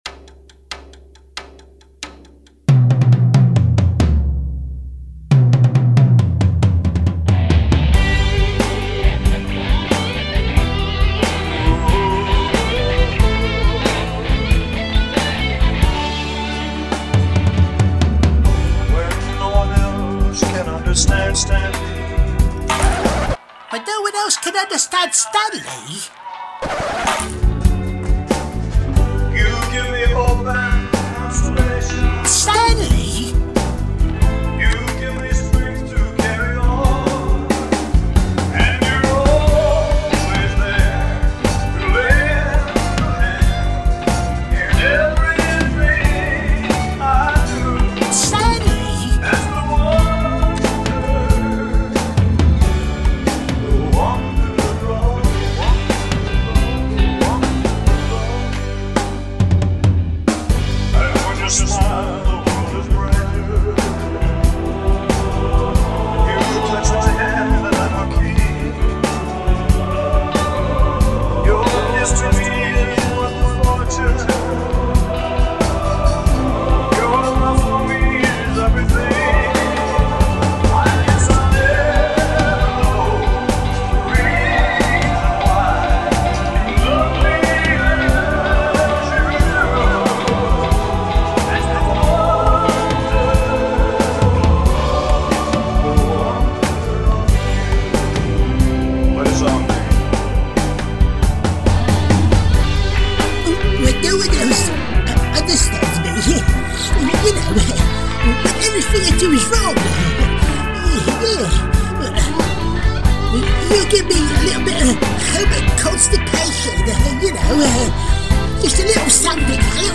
oompah and belly dance
A cover version